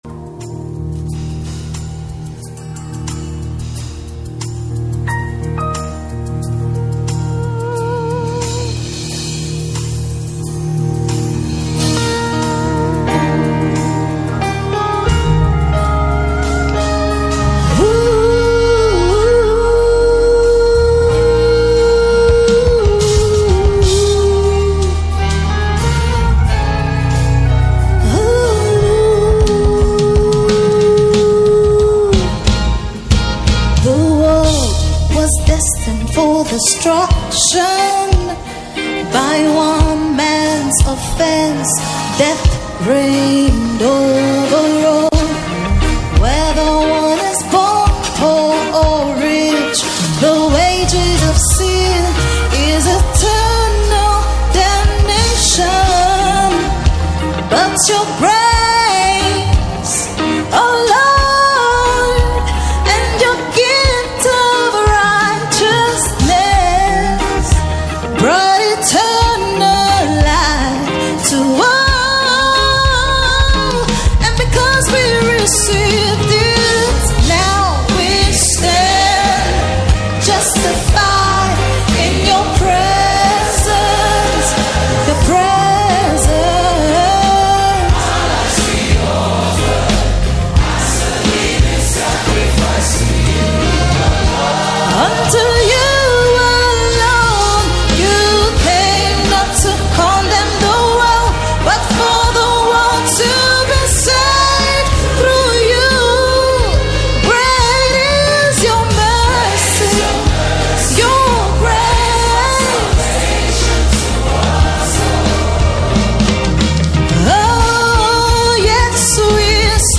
Key – E flat
BPM 90